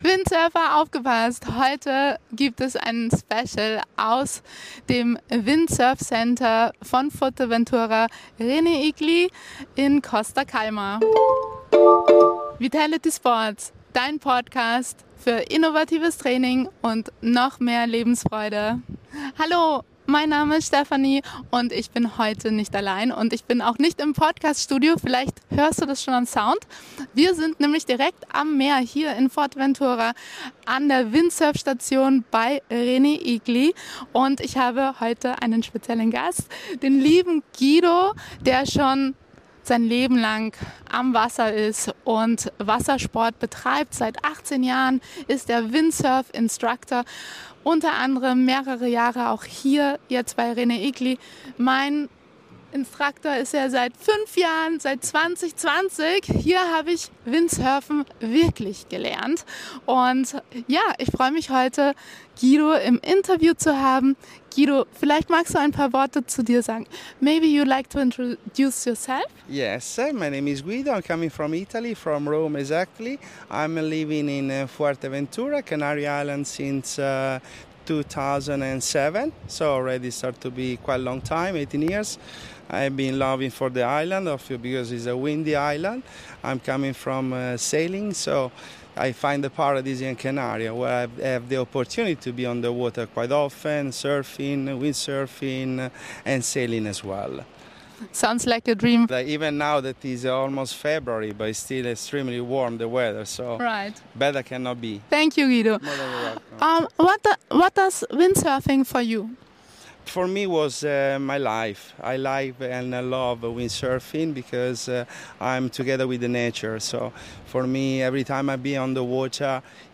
Podcast-Interview, worauf es zu Beginn beim Lernen vom Windsurfen